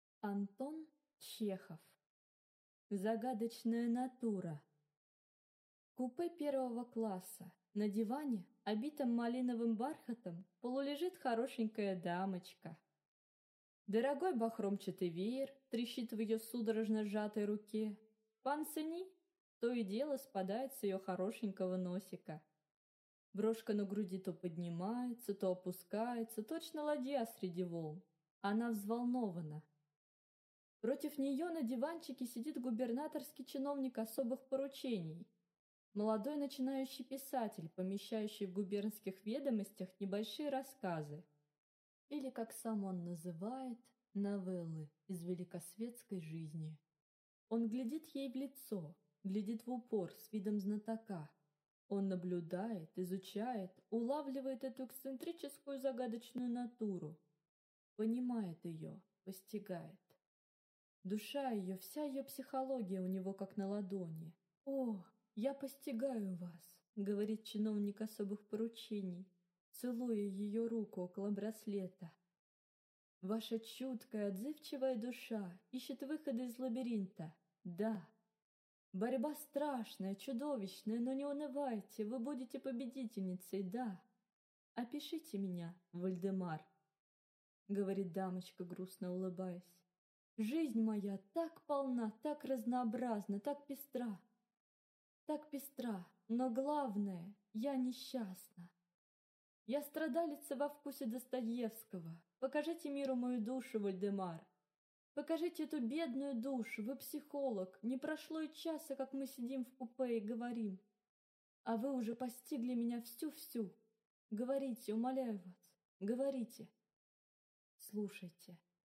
Аудиокнига Загадочная натура | Библиотека аудиокниг